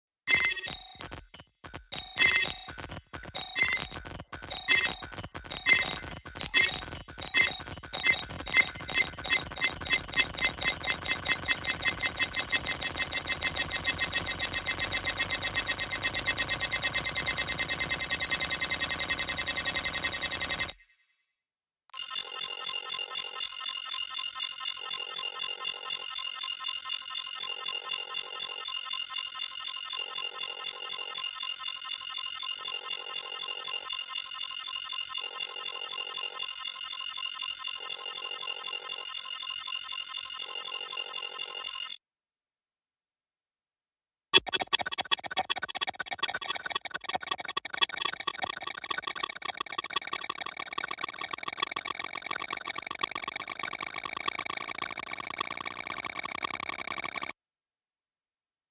Beep click processing, Computer Progress sound effects free download